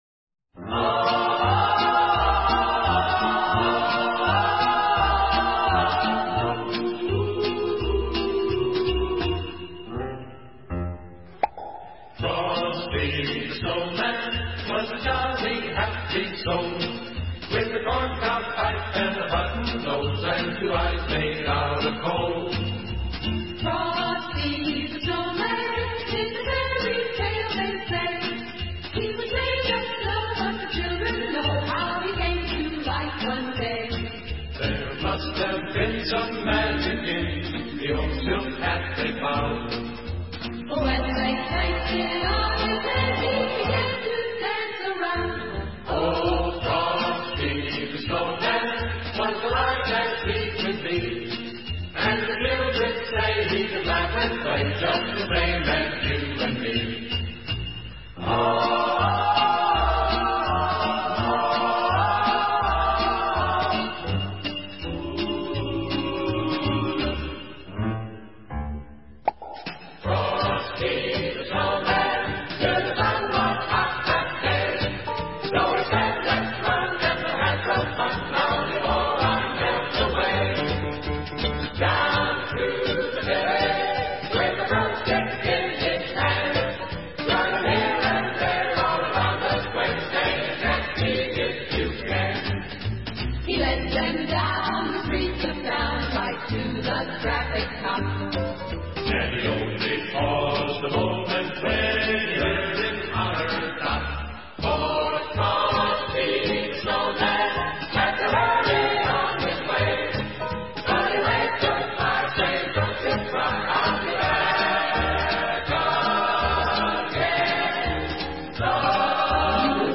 ORQUESTA